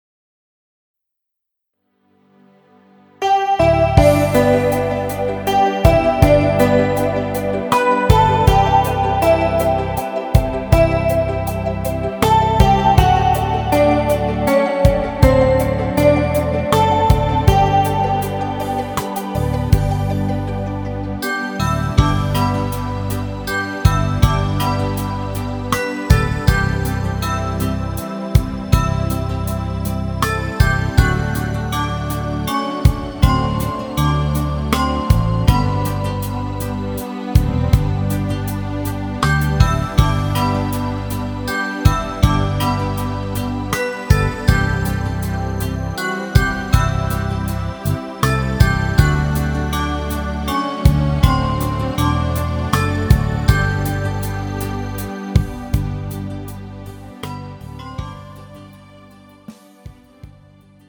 음정 G 키
장르 가요 구분 Pro MR